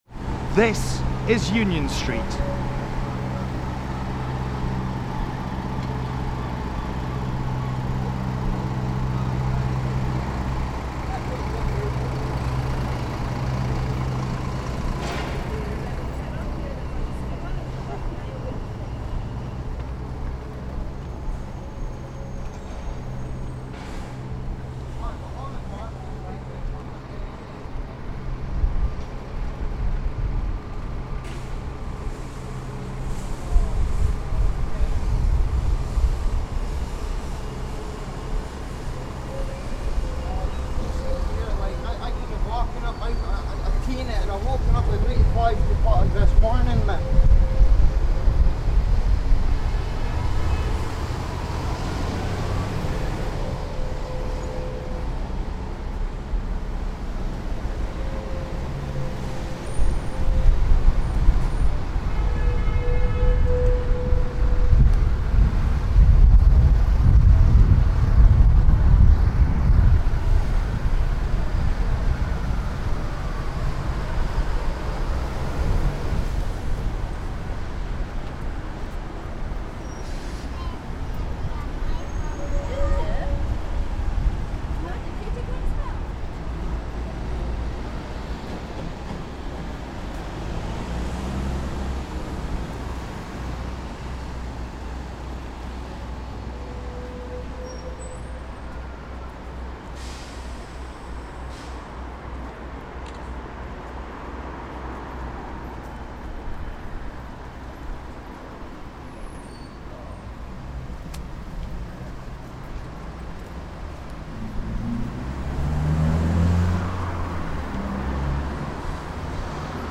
street sounds of scotland